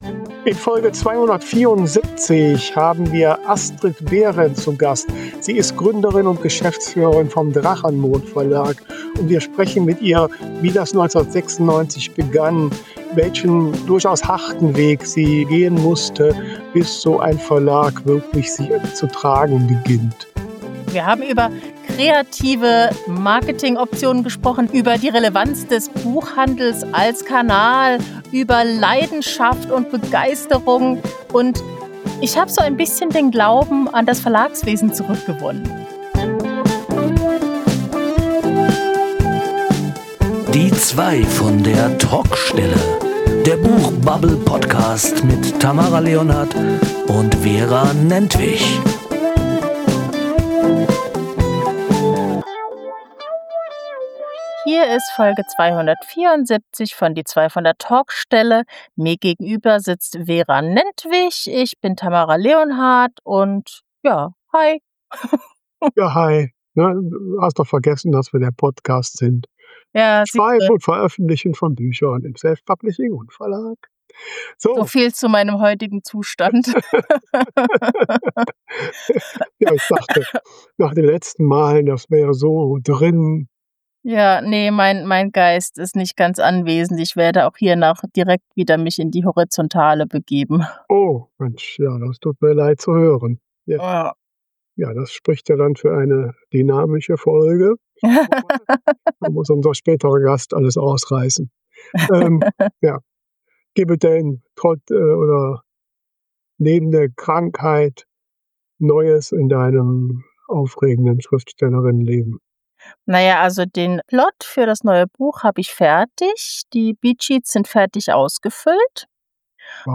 Gespräche über das Schreiben und Veröffentlichen von Büchern, egal ob Selfpublishing oder Verlag.